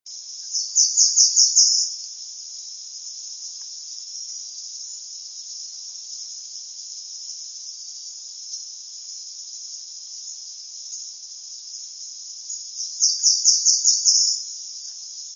Black-Throated Blue Wood Warbler
Black-throated Blue Warbler, Cheesequake State Park, NJ, highest point of Green Trail,  5/7/04 (60kb) migrating with Black-throated Greens, faster, shaker-like sound with ascending suffix
warbler_BT_Blue_795_shaker.wav